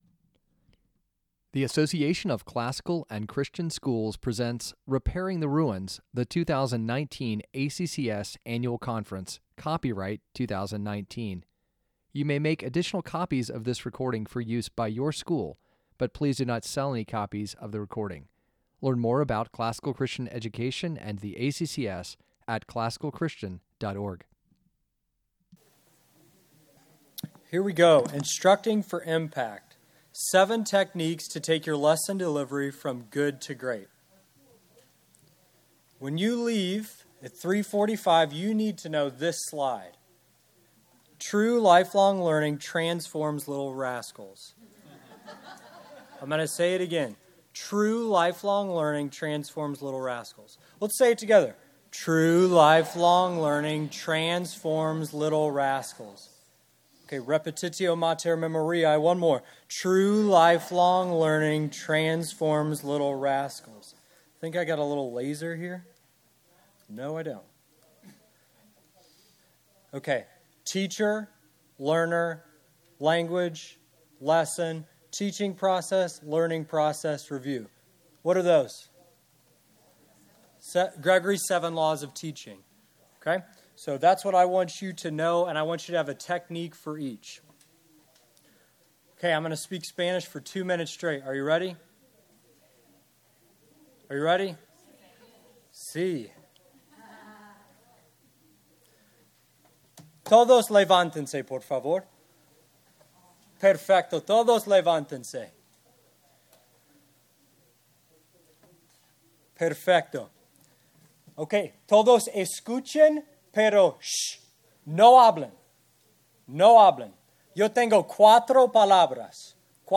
2019 Workshop Talk | 56:06 | All Grade Levels, Teacher & Classroom